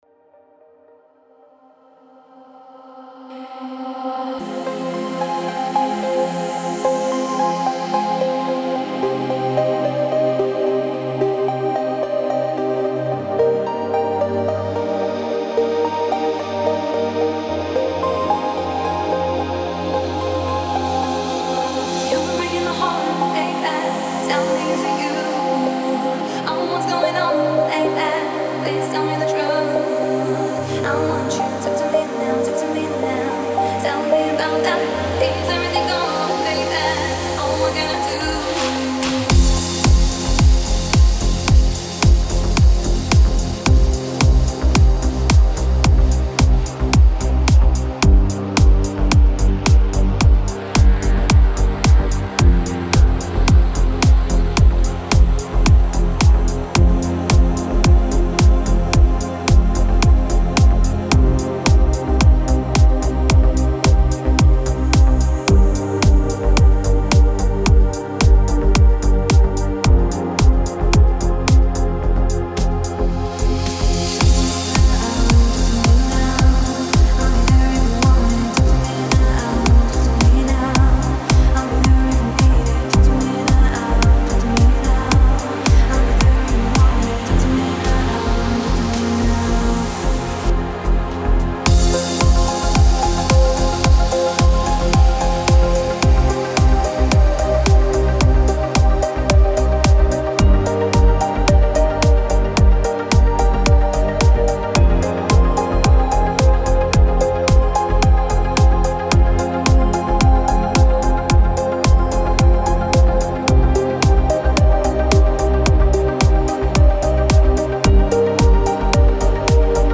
Категория: Deep House музыка
Deep House треки